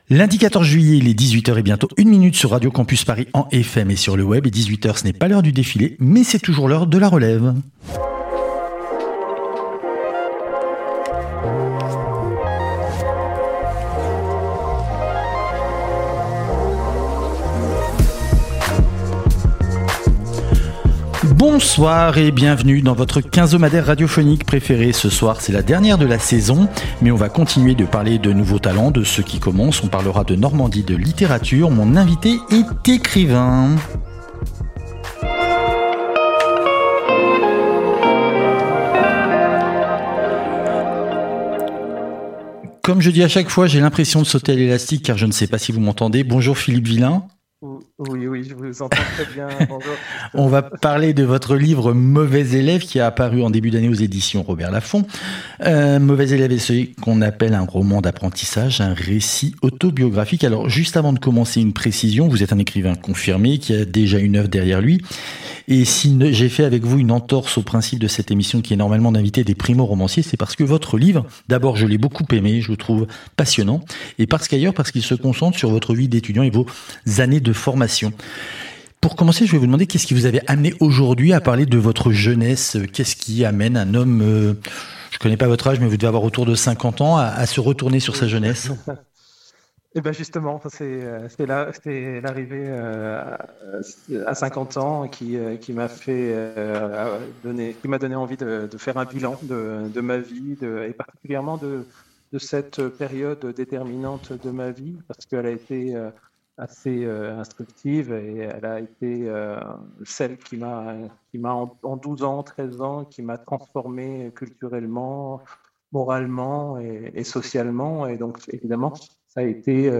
Nous n'étions ni dans une grotte, ni dans une cathédrale, mais on avait un gros problème technique... et on a fait avec les moyens du bord... L'écho créé un effet surréel... un effet Naples sûrement (ou plus prosaïquement, un effet Whatsapp)
Entretien